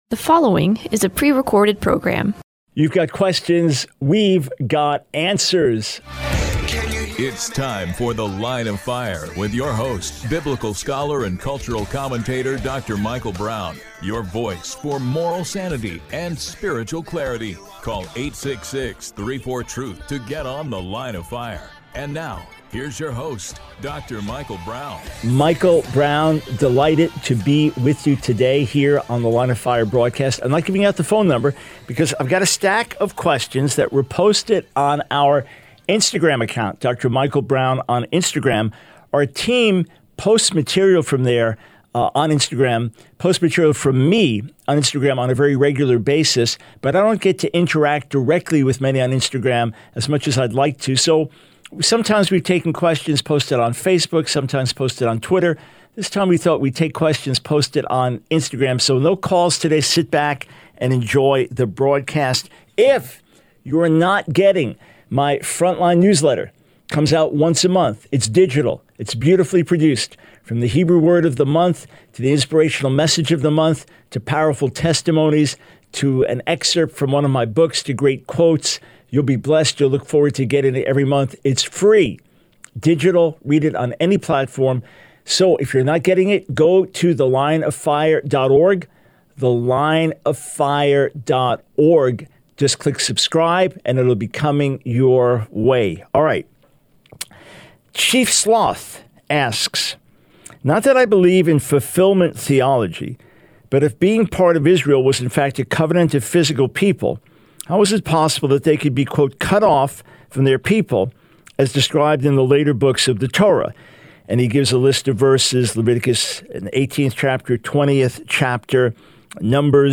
The Line of Fire Radio Broadcast for 08/09/24.